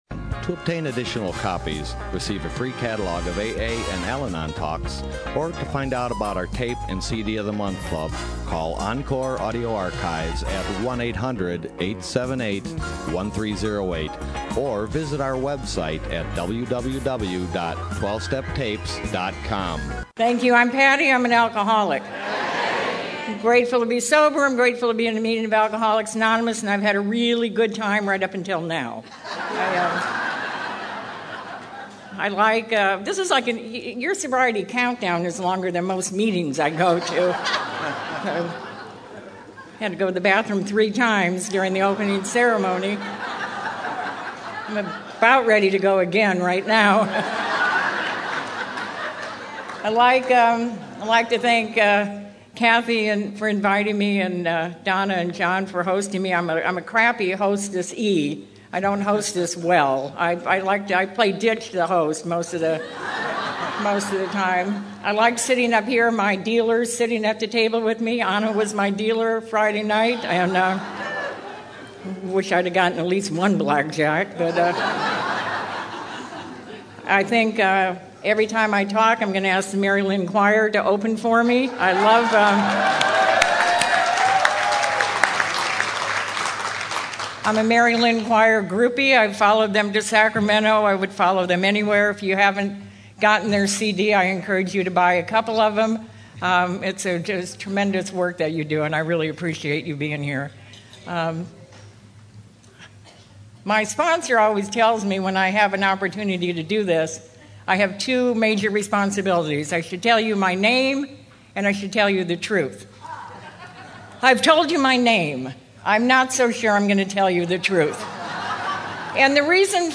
SOUTHBAY ROUNDUP 2012